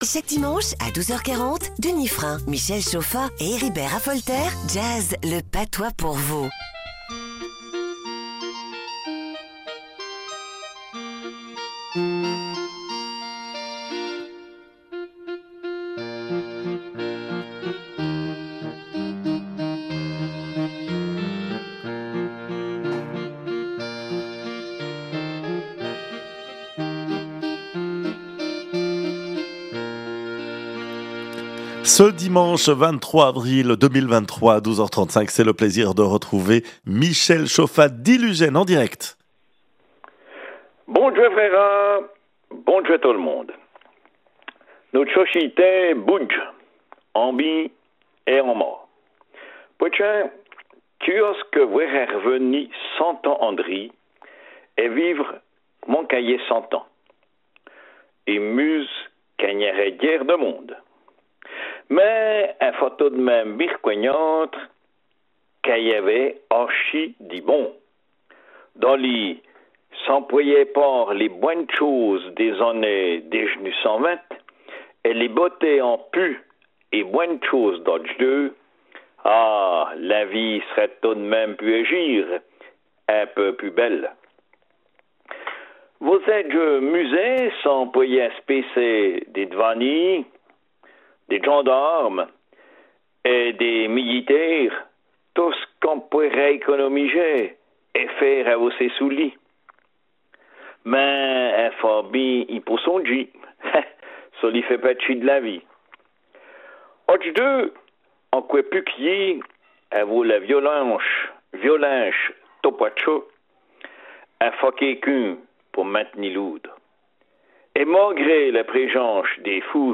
L’Ugène, RFJ, 23 avril 2023 Radio Fréquence Jura RFJ rubrique en patois,